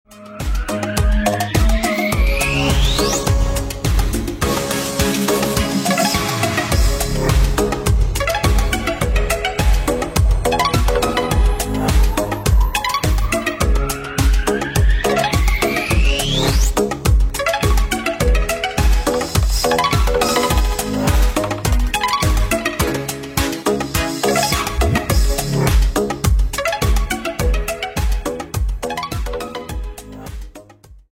зарубежные без слов клубные